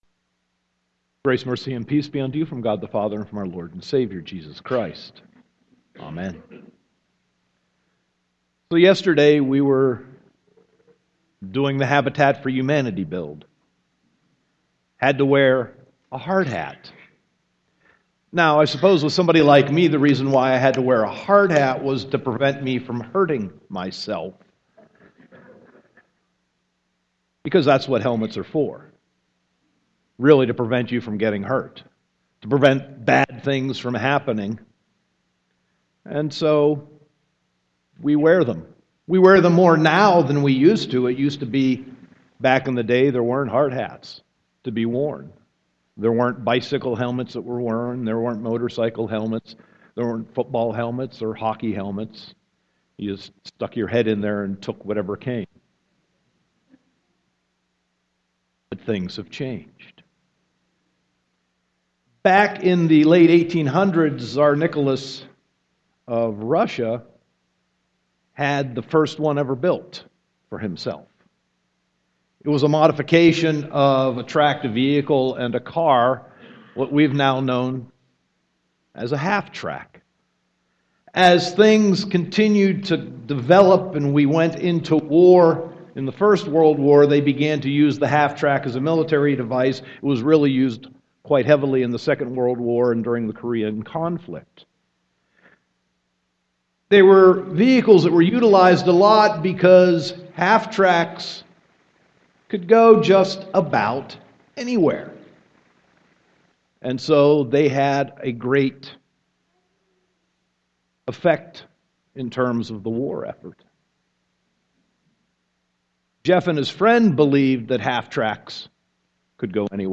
Sermon 2.22.2015